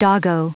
Prononciation du mot doggo en anglais (fichier audio)
Prononciation du mot : doggo